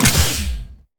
laser-turret-deactivate-01.ogg